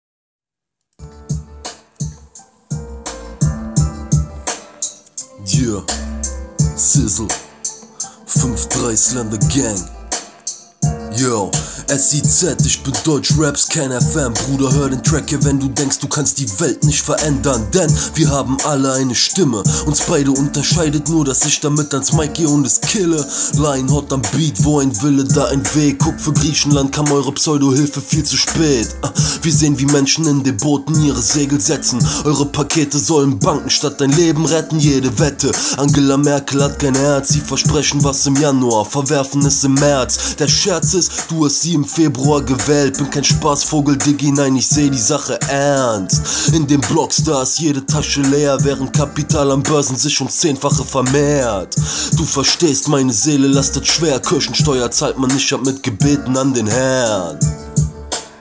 Die sehr simpelen Reime sind durchweg Doppelreime, die letzten 6-8 Zeilen sogar auf die gleichen Silben.
Abschließend habe ich mal einen Beat dazu gebaut und das ganze per Handy-Voicemail im OneTake aufgenommen. Ist selbstverständlich nicht meine gewohnte Studioqualität, aber um den Flow rauszuhören dürfte es ausreichen.